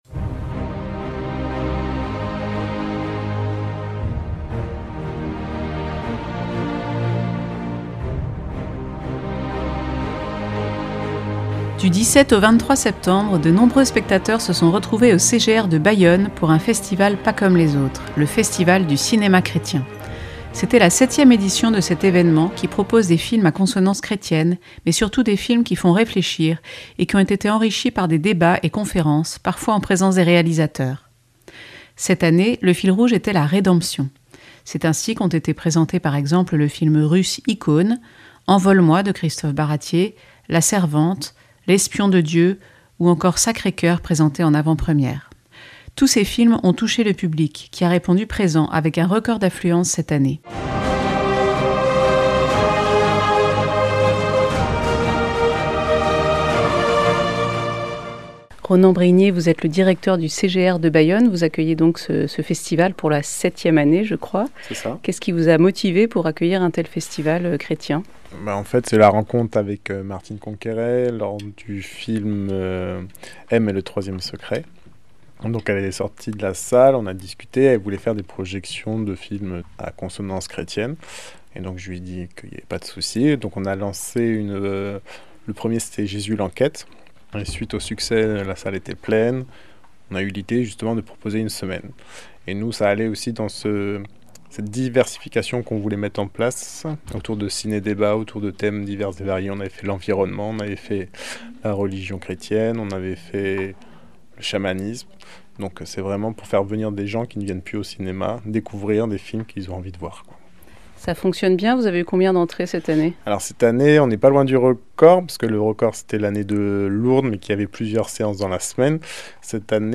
La réponse dans ce reportage.
Journaliste